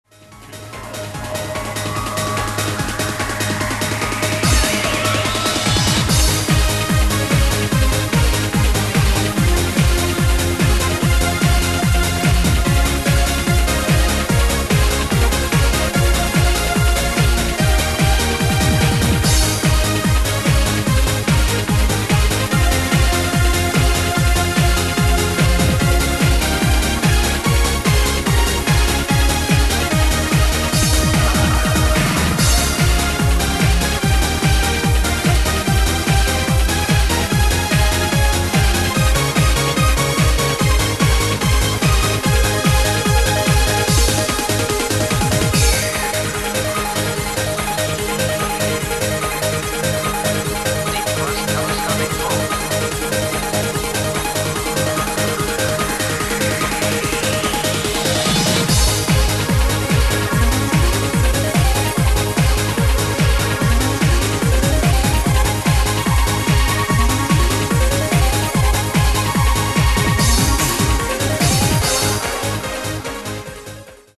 往来のファミコンVGMをドリームテクノでリメイク&アレンジ。